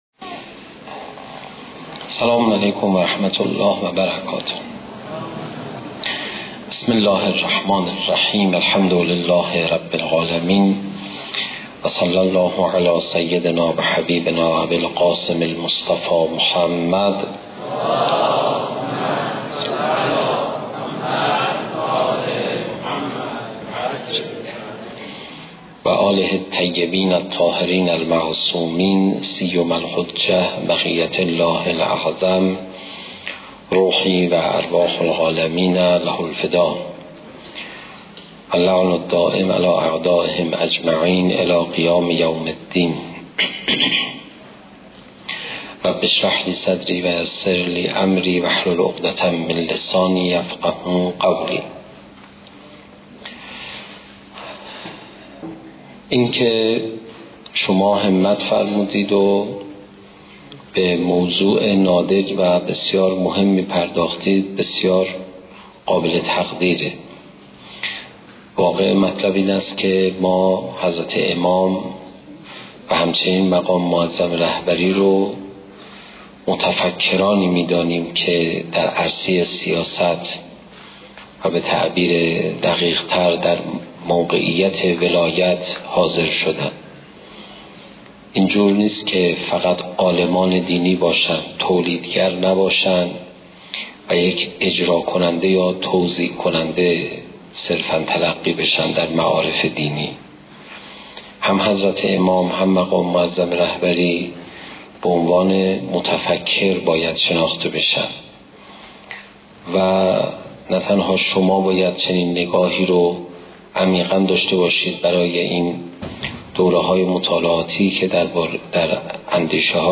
سخنرانی حجت الاسلام پناهیان درمورد ولی فقیه